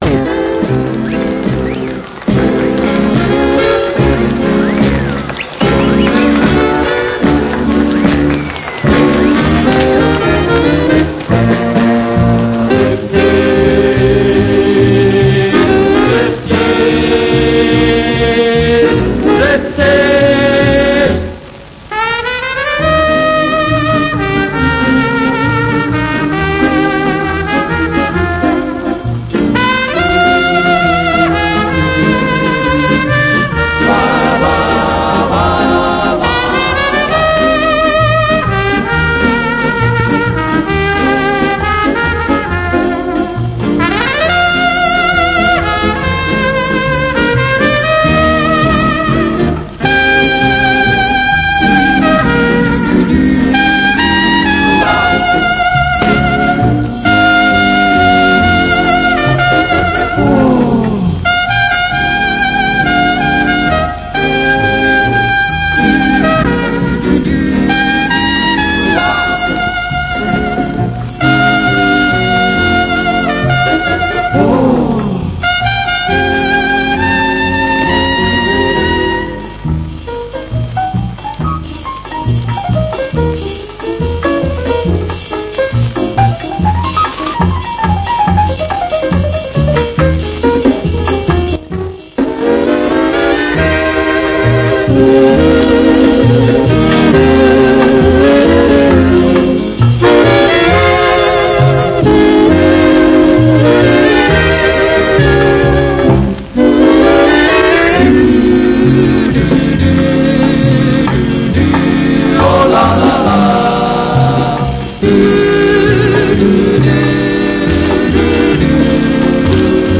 По большей части записи сделаны с КВ-радиостанции Лахти (кроме 13-16) в районе 1960 года (±2-3 года) и оцифрованы.
Неизвестный исполнитель – неопознанная композиция (инструментал).